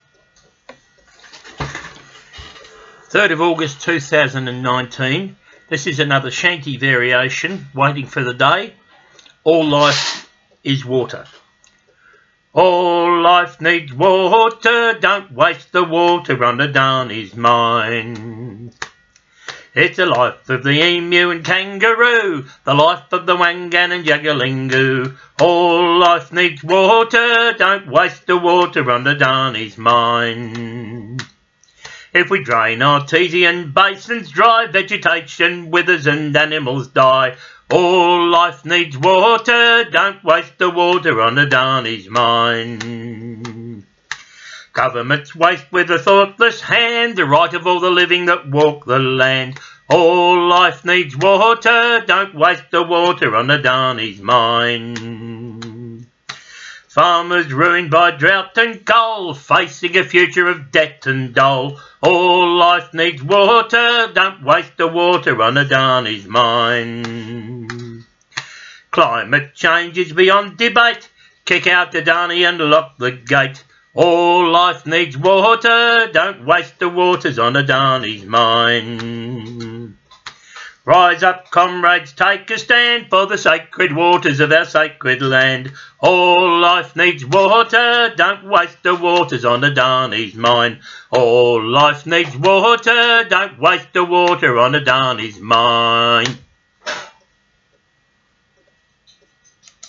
protest song